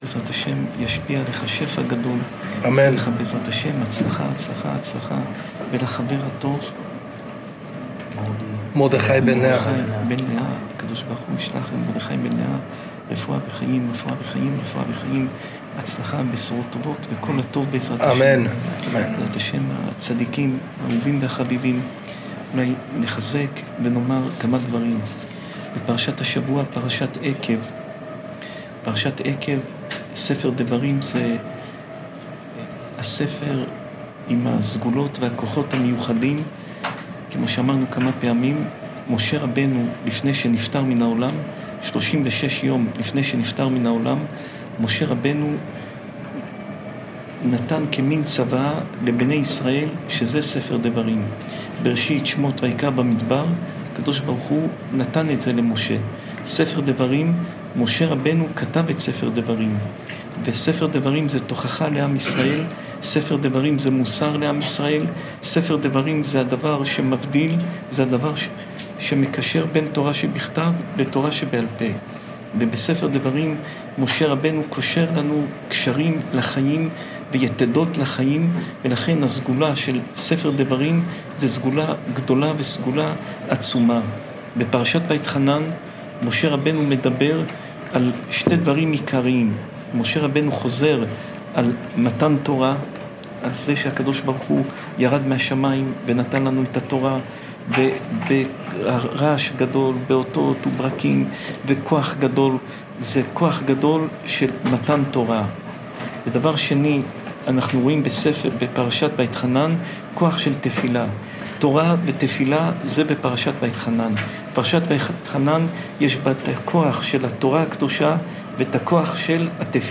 שעורי תורה מפי הרב יאשיהו יוסף פינטו